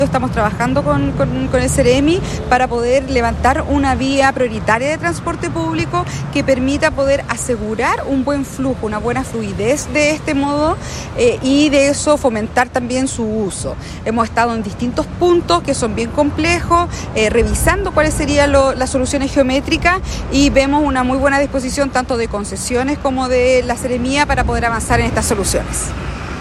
Por su parte, Alejandra Maureira, SEREMI de Transportes y Telecomunicaciones, señaló que